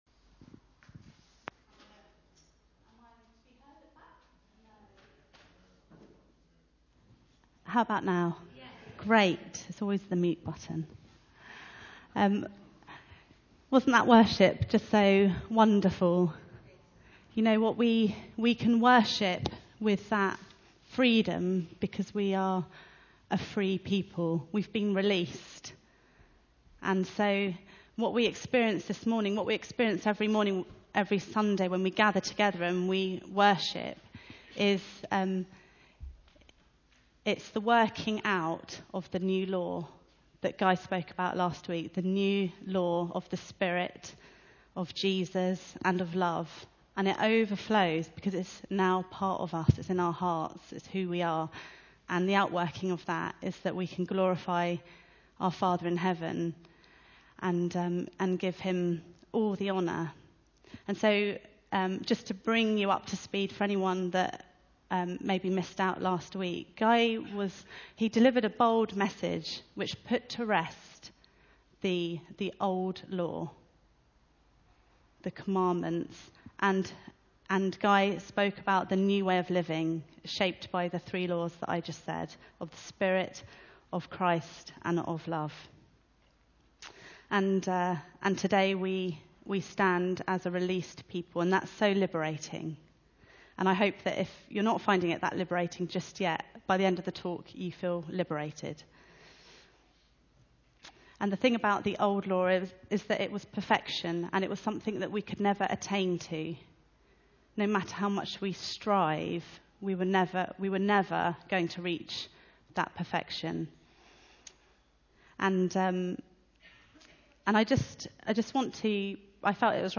Romans 7:7-25 Service Type: Sunday Meeting Bible Text